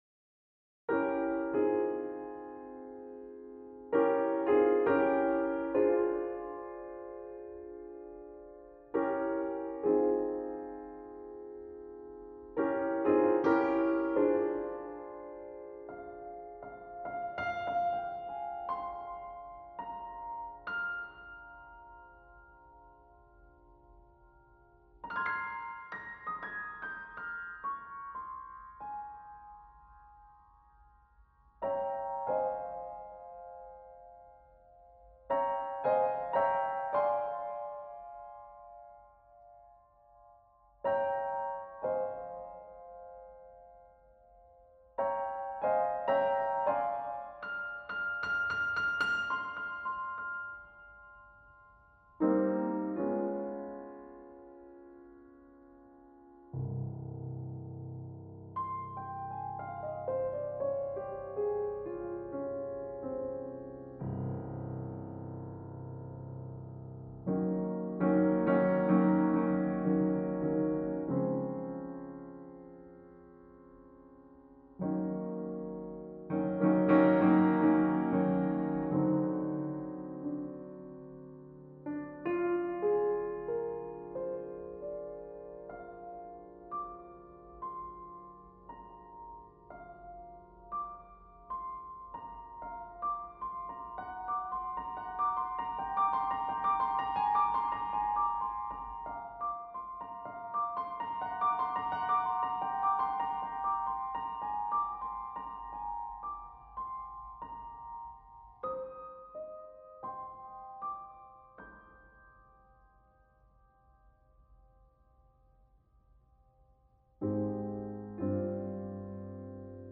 Writer's block brought on a little free association on the ivories... pretty happy with the outcome.